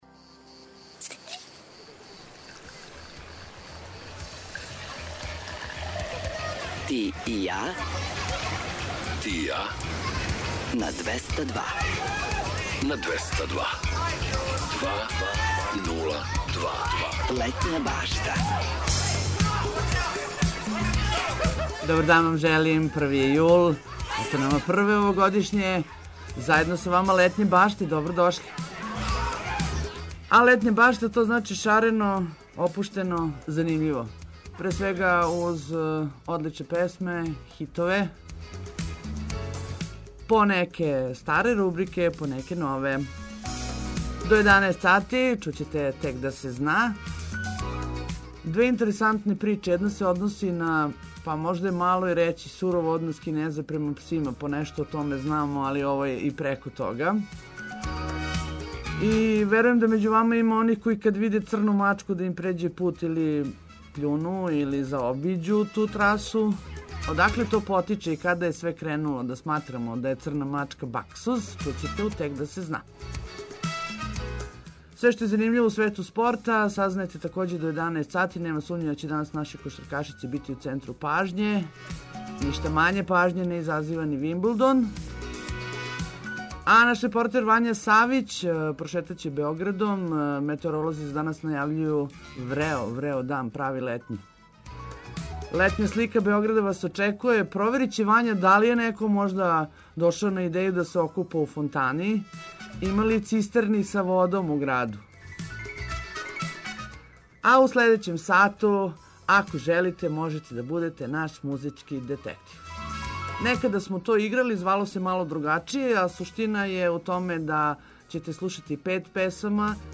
Будите музички детектив, забавите се уз занимљиве приче, спортске вести и слике наших репортера. Слушајте своје омиљене песме.